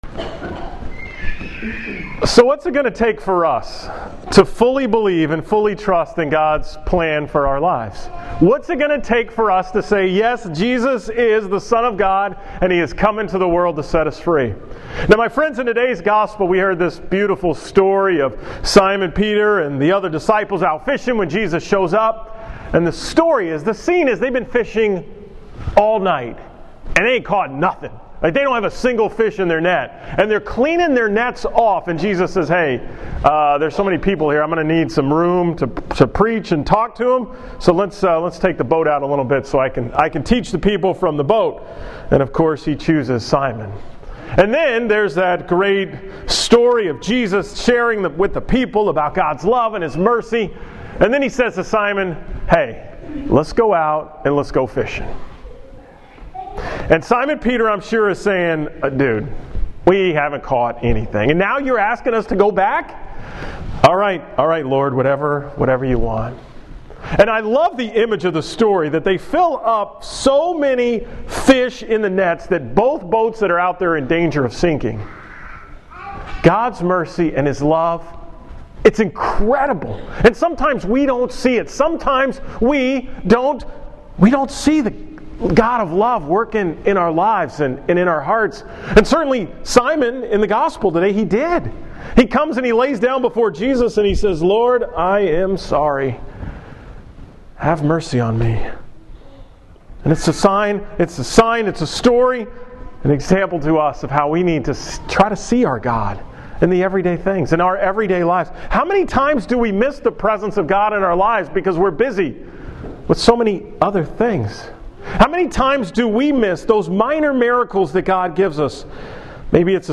From the Mass at Immaculate Conception in Sealy on February 7, 2016